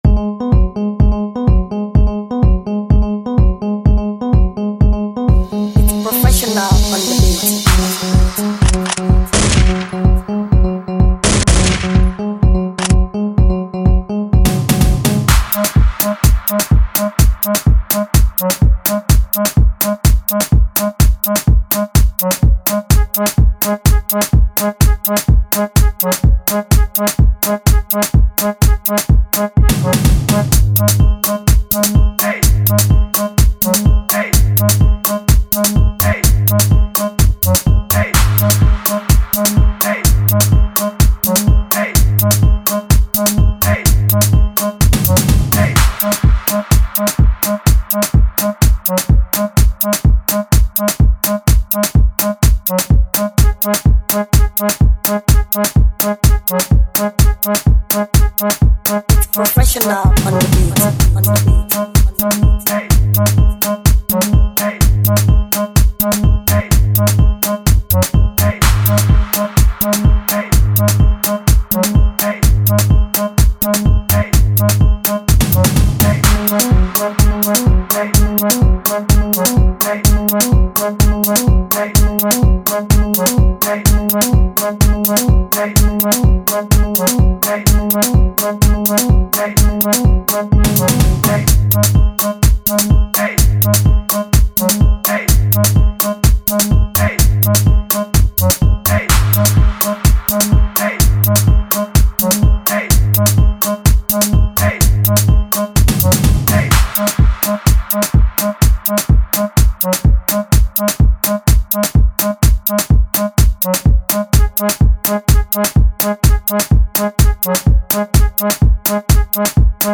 freebeat